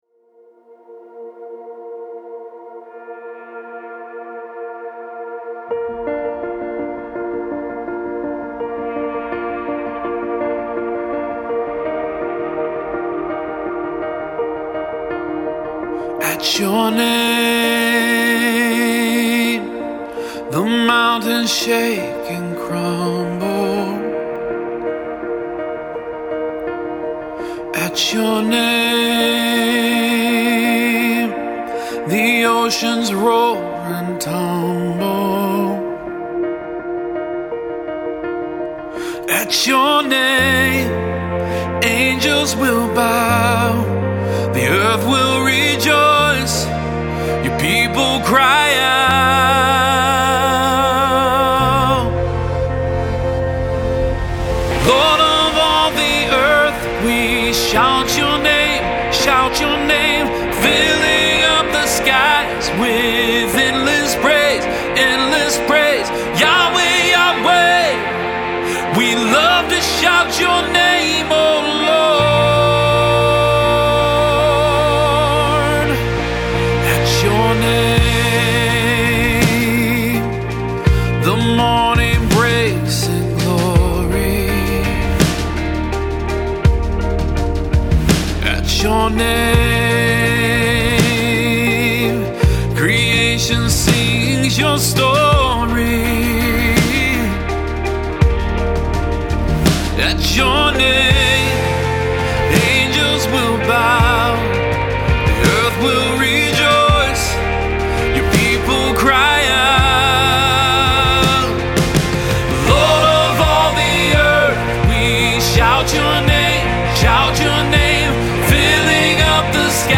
Full arrangement demo
• Tempo: 83 bpm, 4/4 time
STYLE: RE-ARRANGEMENT
• Drums
• Electric Guitar (2 tracks)
• Piano (2 tracks)
• Bass
• Pads
• Synth
• BGVs
• Vocal Double